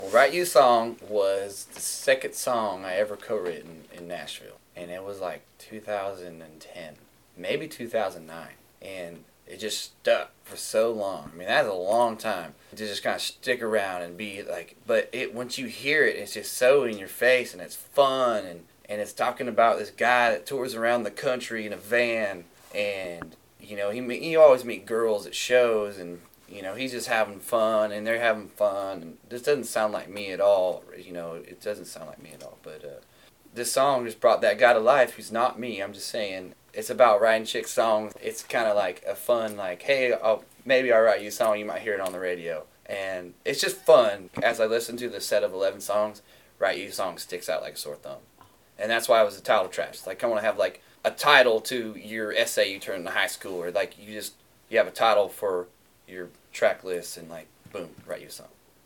:51  NOTE: Audio isn’t the best quality.